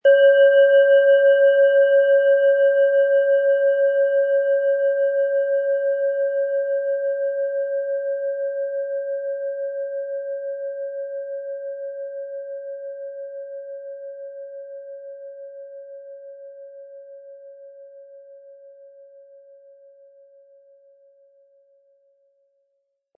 OM Ton
Im Preis enthalten ist ein passender Klöppel, der die Töne der Schale gut zum Schwingen bringt.
MaterialBronze